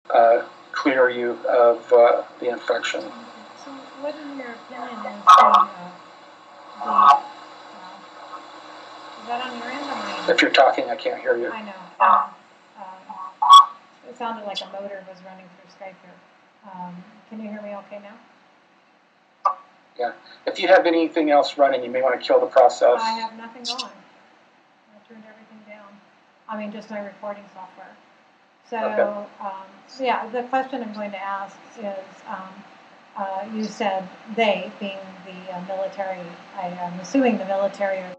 A major new interview with a Project Avalon Whistleblower
Audio_noise_in_C_2noises_decreased_+_HP.mp3